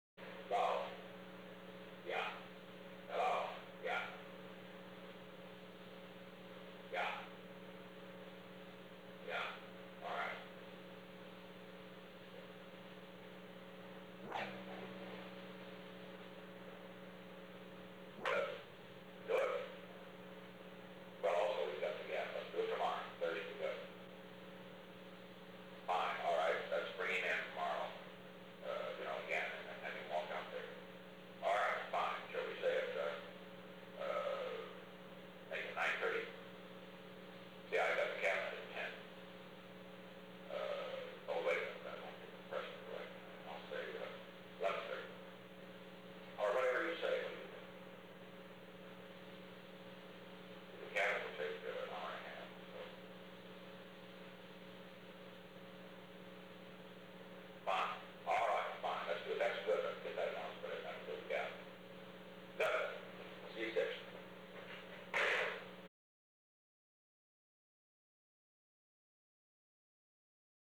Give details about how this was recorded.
Secret White House Tapes Location: Executive Office Building